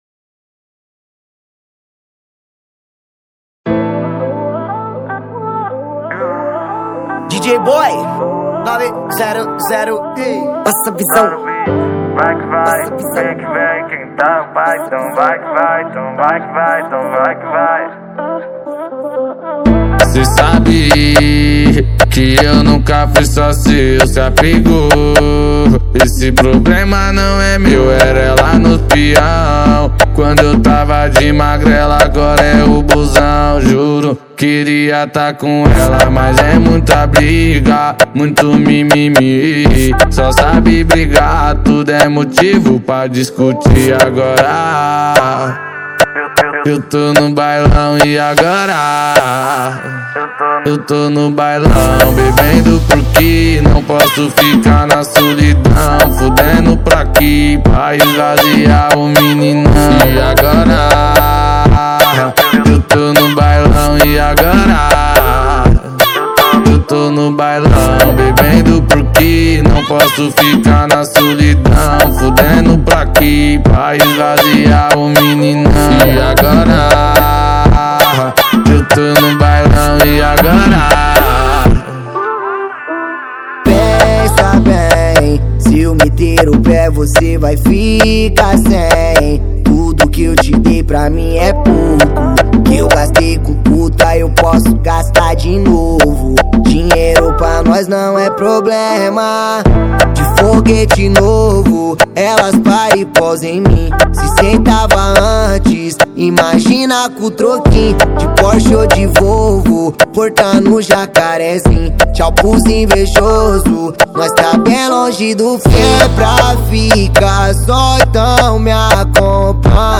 Gênero: MPB